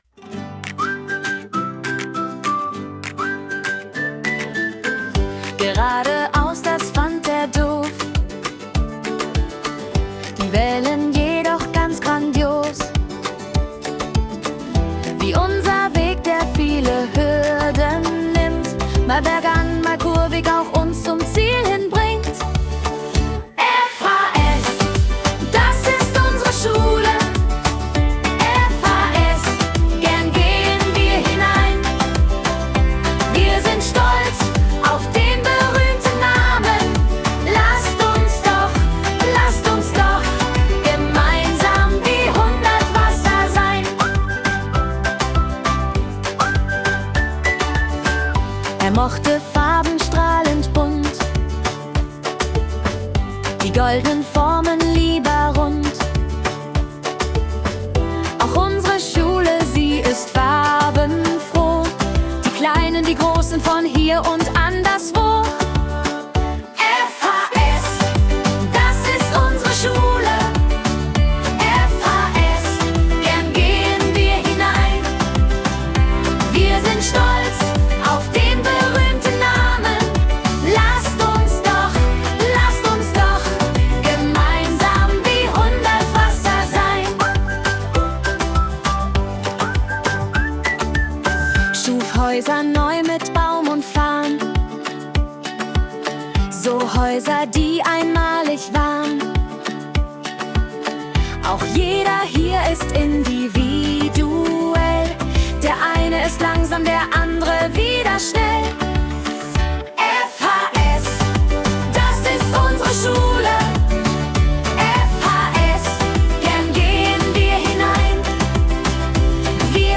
Die Melodie wurde mit KI-Unterstützung erstellt.